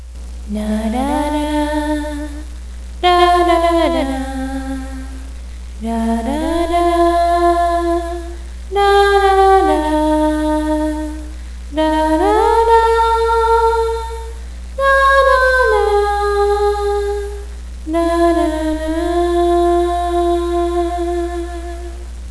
- - v o c a l    .w a v - -
unknown melody.